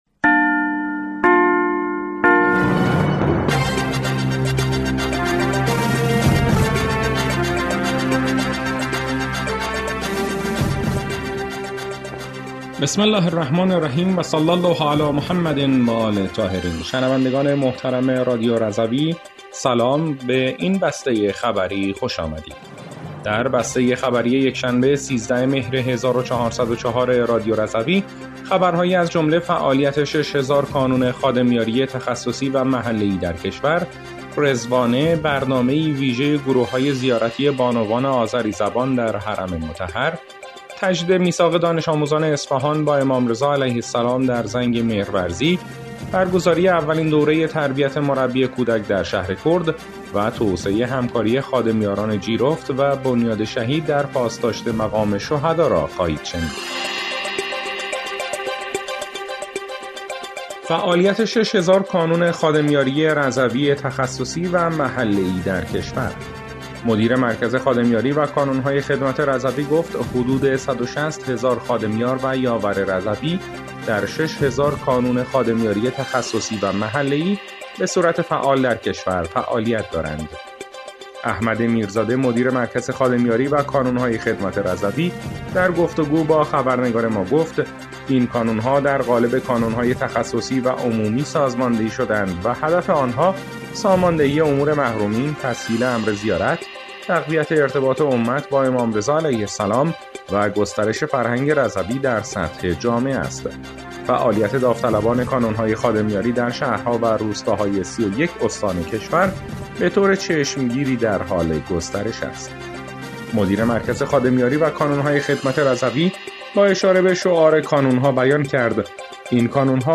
بسته خبری ۱۳ مهر ۱۴۰۴ رادیو رضوی؛